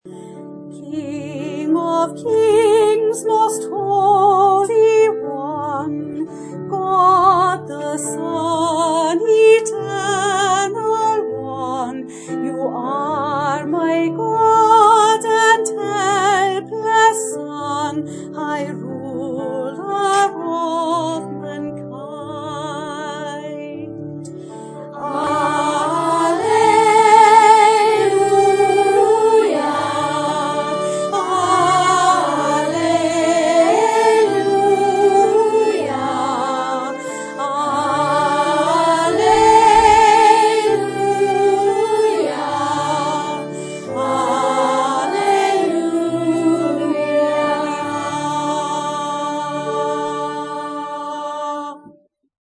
Lullaby
to download and listen to the last verse and chorus featuring a year 7 choir.